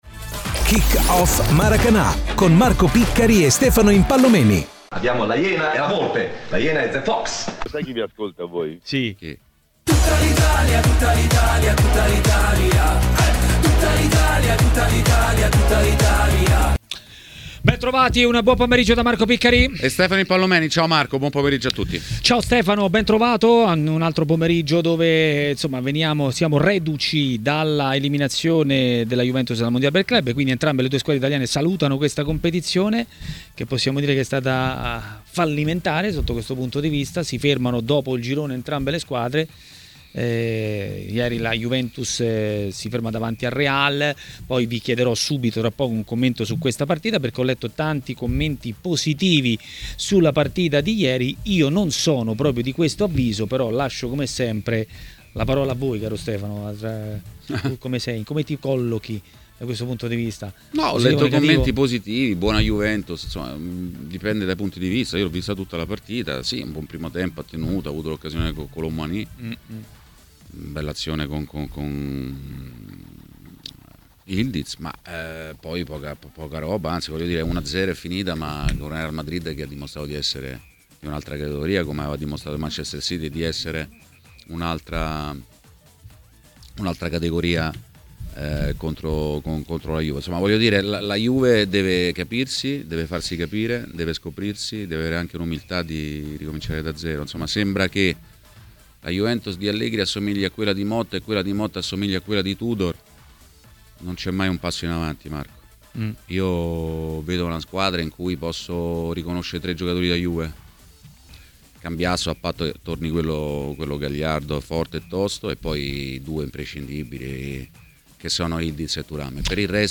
L'ex calciatore Massimo Paganin ha parlato di Inter a Maracanà, nel pomeriggio di TMW Radio.